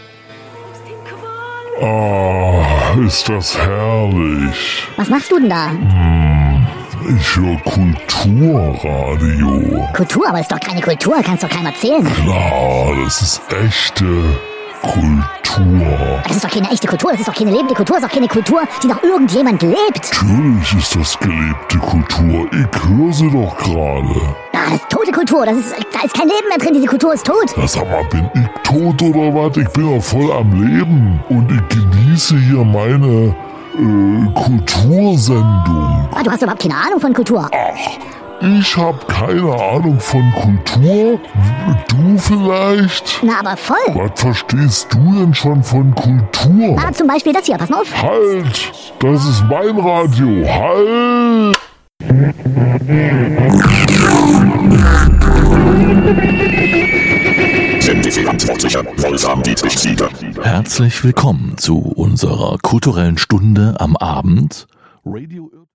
Und wie immer dürft Ihr im Fediverse schon eine Woche vorher das Kurz-Hörspiel-Intro hören (ft. Arnold Schönberg) 😀 .